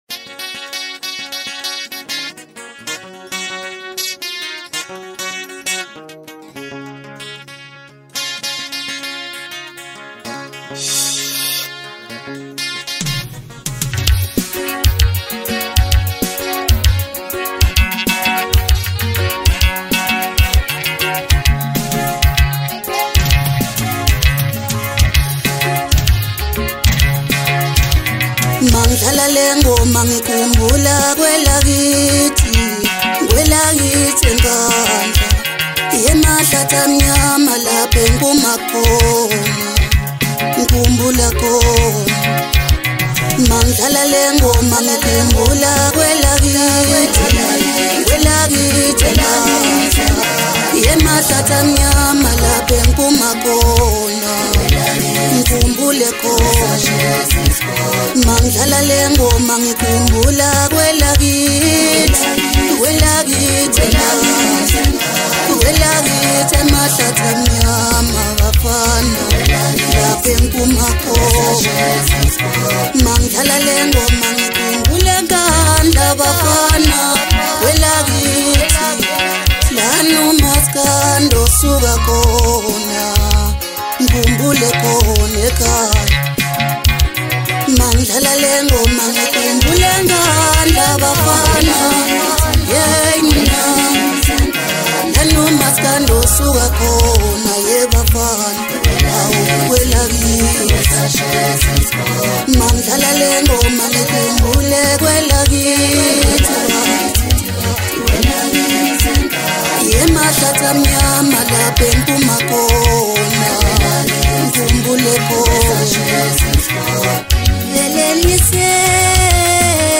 December 29, 2025 admin Maskandi 0